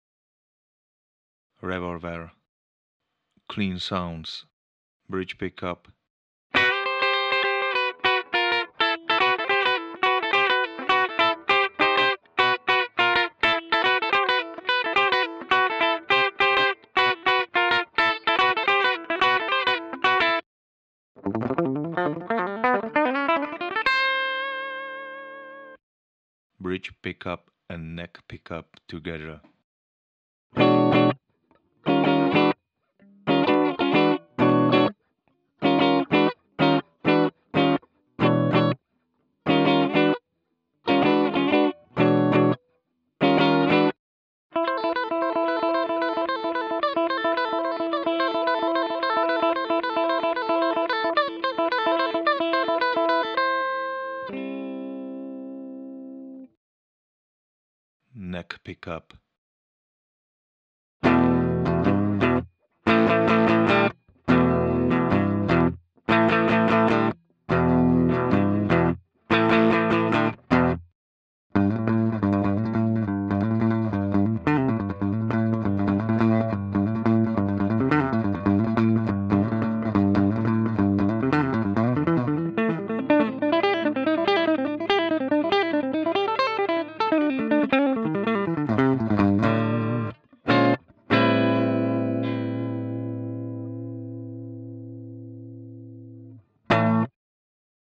revolver_clean.mp3